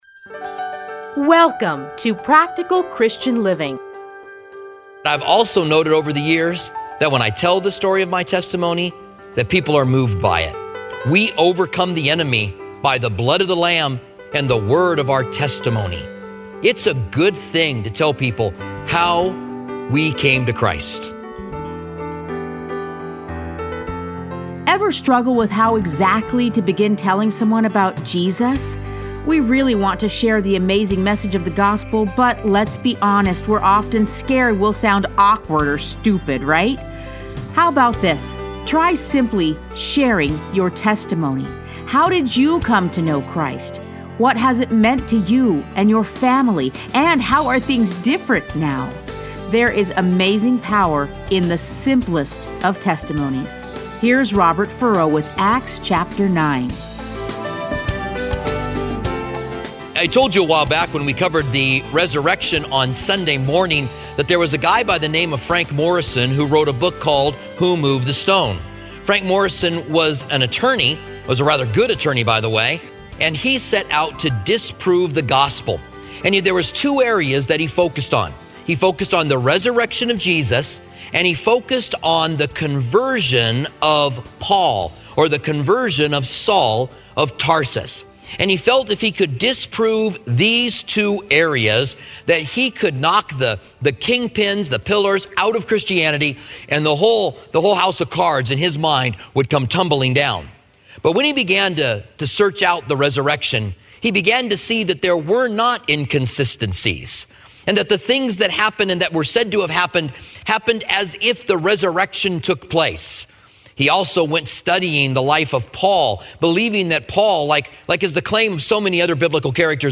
Listen to a teaching from Acts 9.